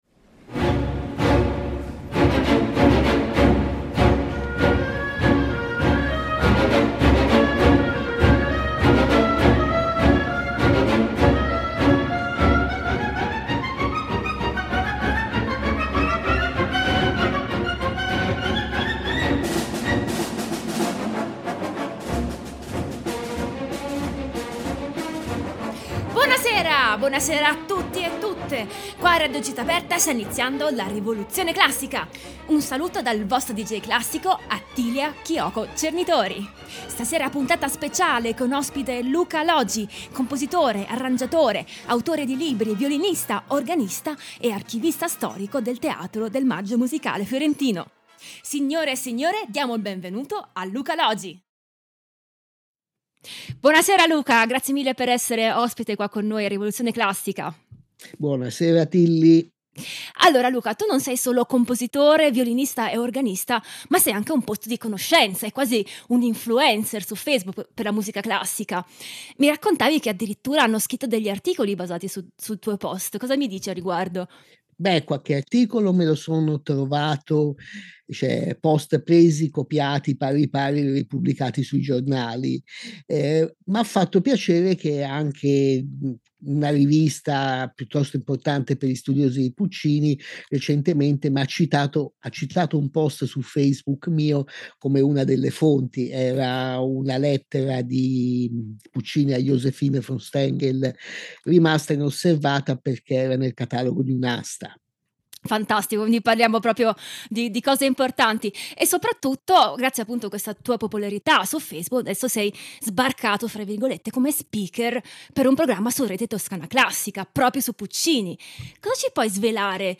Anna Netrebko soprano Orchestra Filarmonica di San Pietroburgo
José Carreras e Plácido Domingo, tenori